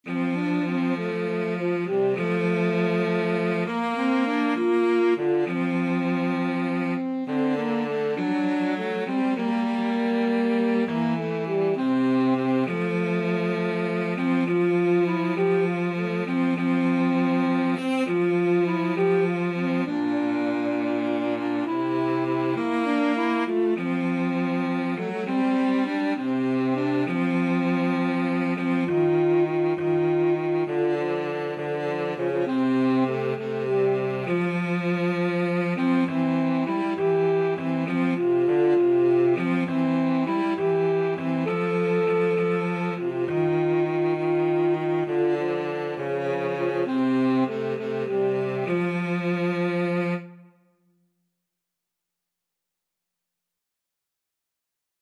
Alto SaxophoneCello
6/8 (View more 6/8 Music)
Classical (View more Classical Saxophone-Cello Duet Music)